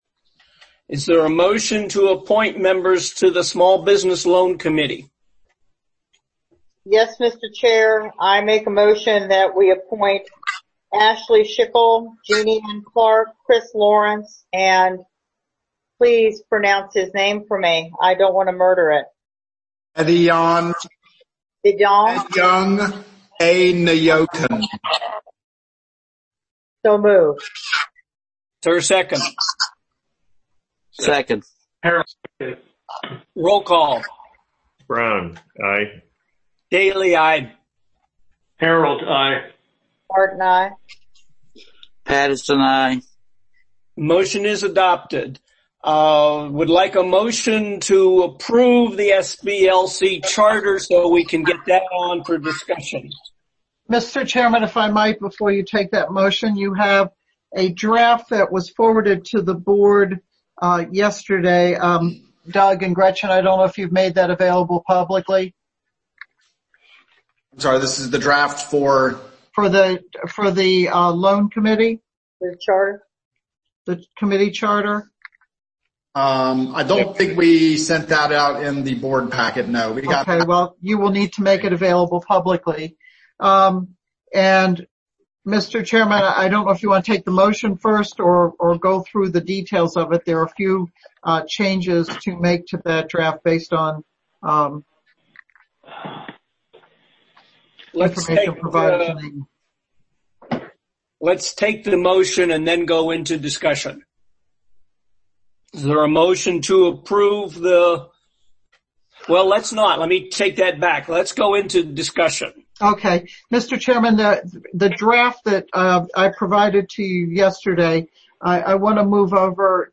Are we ready to meet? – The EDA prepares for its virtual monthly meeting of Friday, April 24.